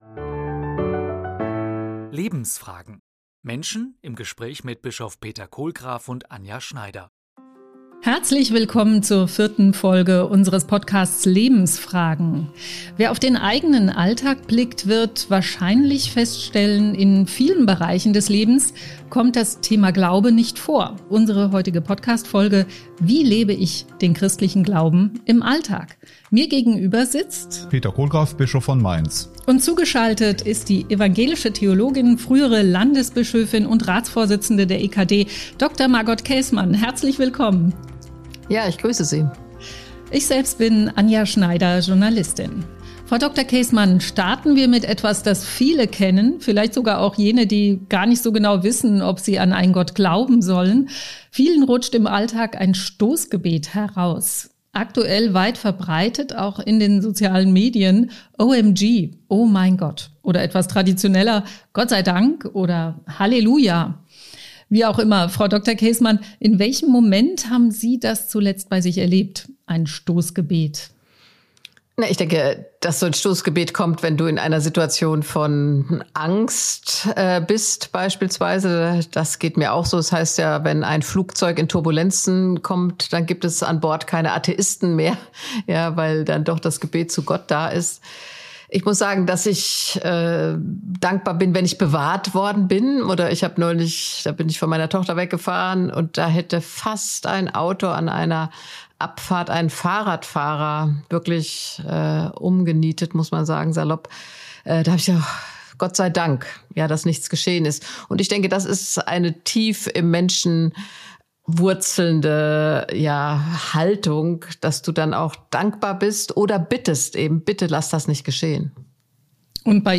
Lebensfragen - Menschen im Gespräch mit Bischof Peter Kohlgraf
Zu Gast: Margot Käßmann. Ob spontanes Stoßgebet, regelmäßige Gebetszeiten oder besondere Gebetsformen: Das Gebet ist ganz wesentlich für den Glauben und die Beziehung zu Gott.